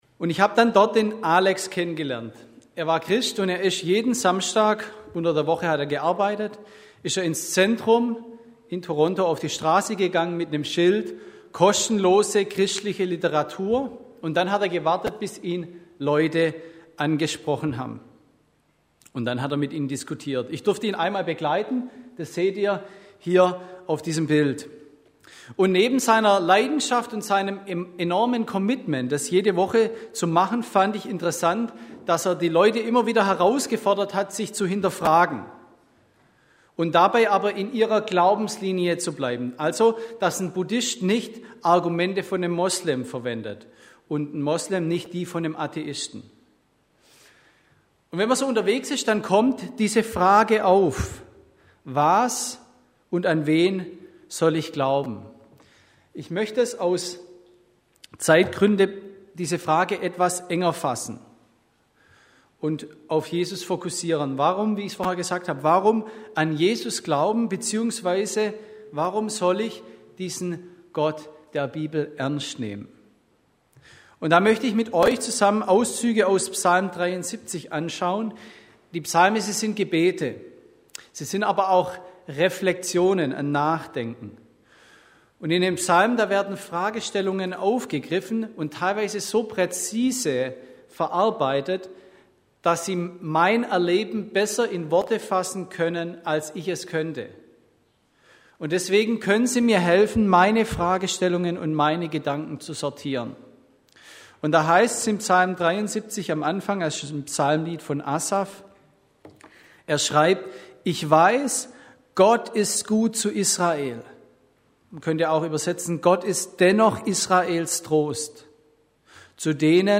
– Predigten: Gemeinschaftsgemeinde Untermünkheim